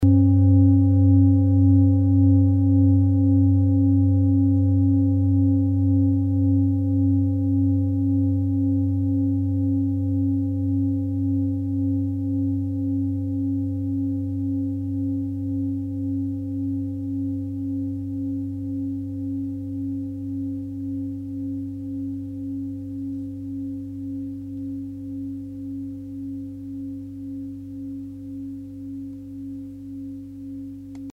Klangschale Nepal Nr.47
Klangschale-Durchmesser: 25,0cm
(Ermittelt mit dem Filzklöppel)
Wenn man die Frequenz des Mittleren Sonnentags 24mal oktaviert, hört man sie bei 194,18 Hz.
Auf unseren Tonleiter entspricht er etwa dem "G".
klangschale-nepal-47.mp3